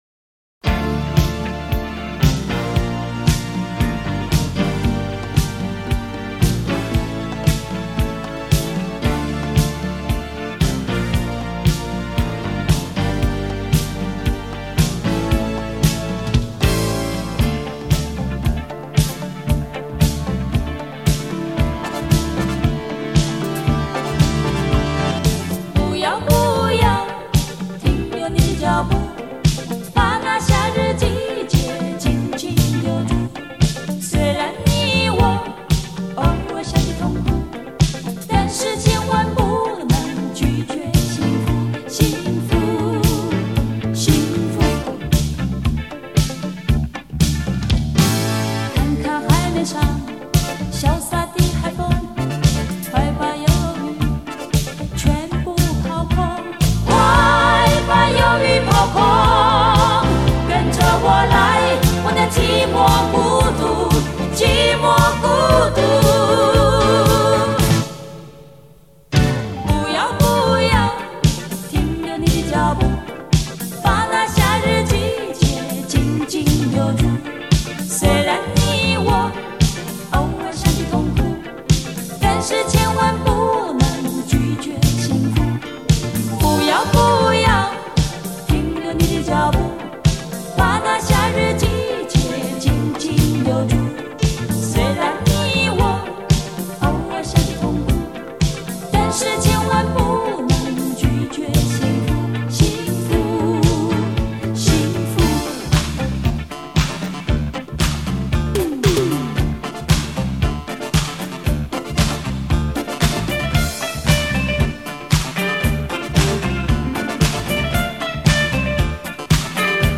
满足每对殷殷期盼的金耳朵--音色乐器100%还原录音临场感